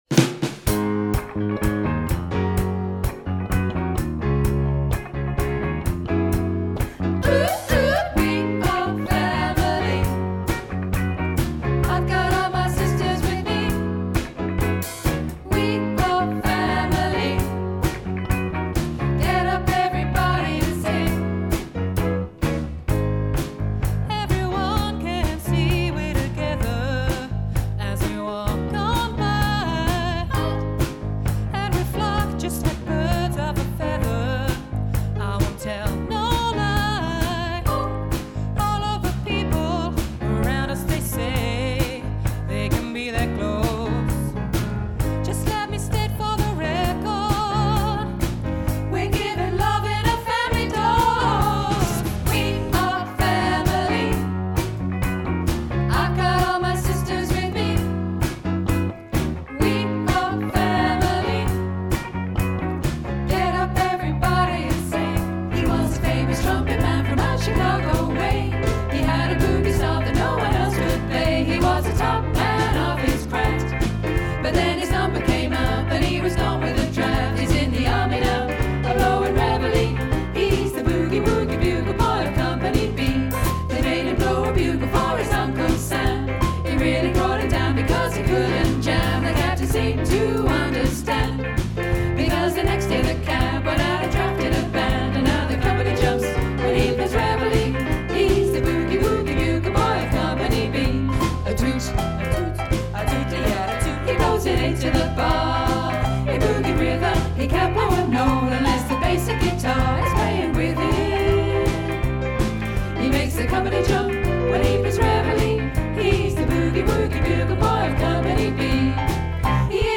Here it is: Volume 1 of our megamix from a recent live session with Flat Broke, our band. Have a listen to our lively party/disco tunes.
accsfb_megamix.mp3